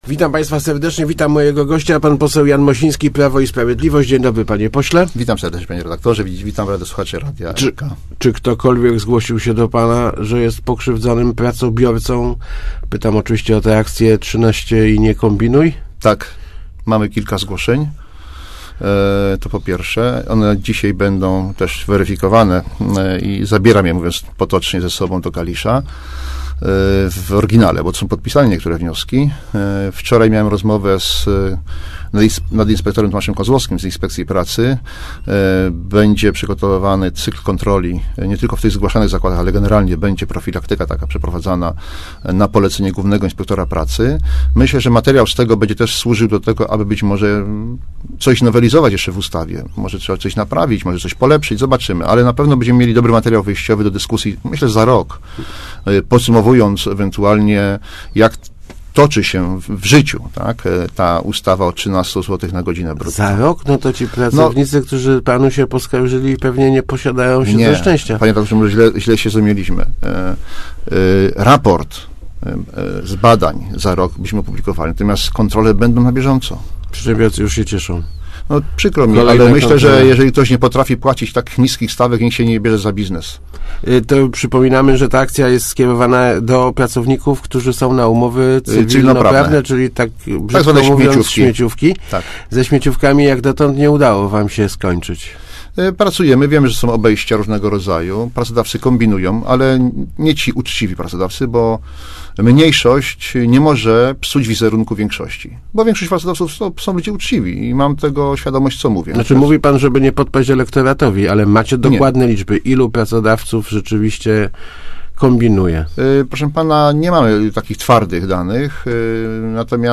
Chcemy ucywilizowa� agencje pracy tymczasowej - mówi� w Rozmowach Elki pose� Jan Mosi�ski (PiS). Przygotowywana jest w�a�nie ustawa, która ma da� wi�cej uprawnie� zatrudnionym w takich agencjach: dotyczy to na przyk�ad kobiet w ci�zy lub zatrudnianych przez d�u�szy czas.